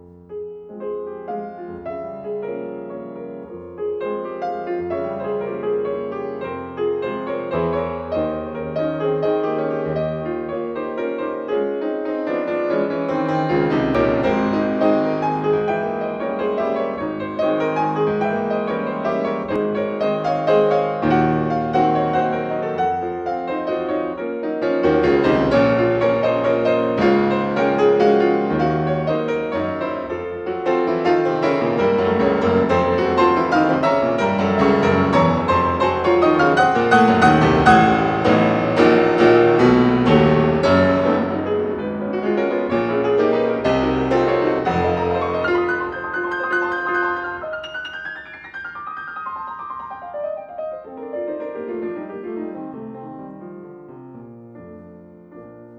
Al Pianoforte